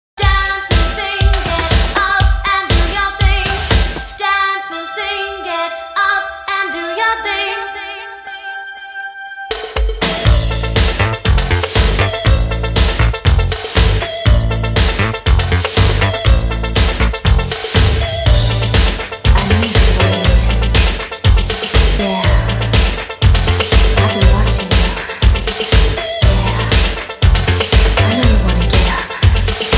Pop / Dance / Remix / Electronic